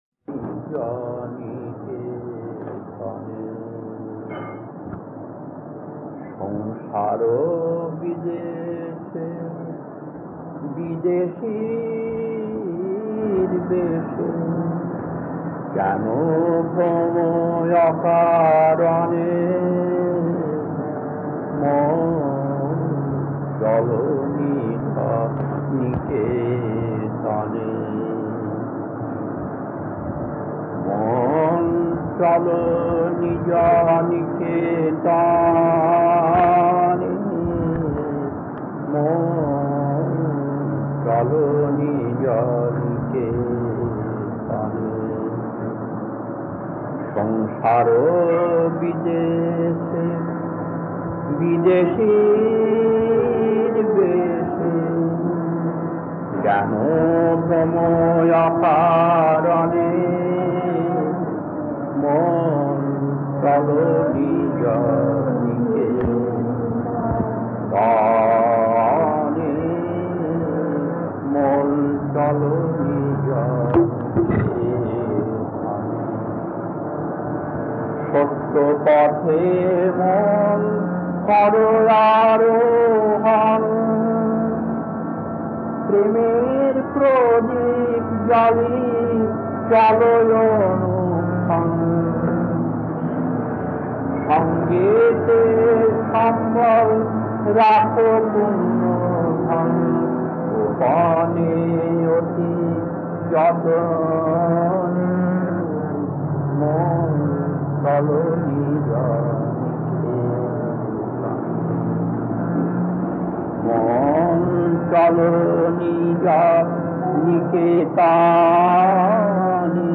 Kirtan D4-1 1.